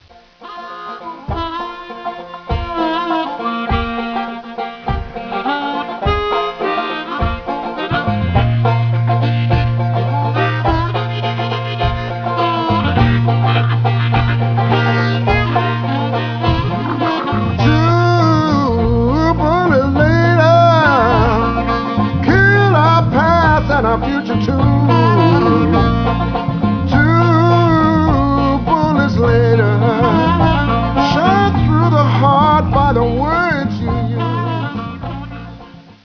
Keyboards, acoustic piano
Percussion
Electric bass